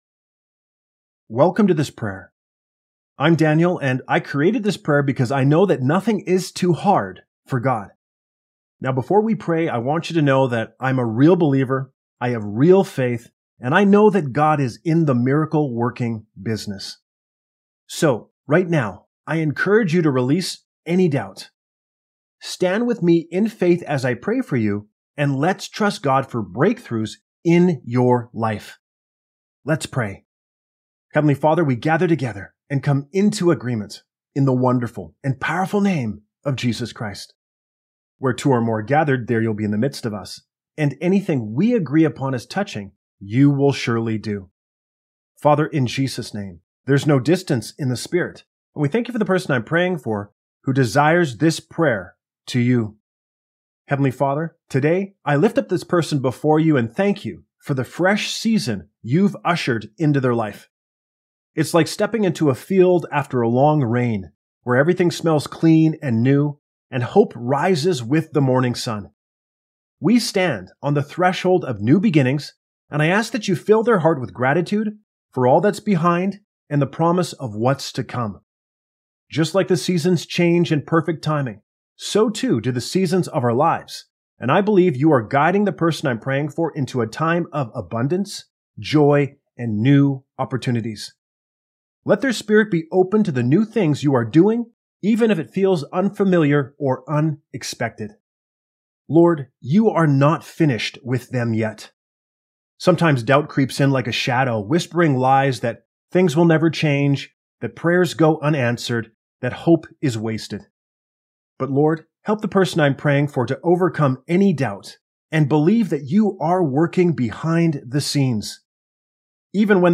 All you have to do is listen and agree as he prays.